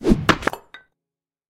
axe.ogg.mp3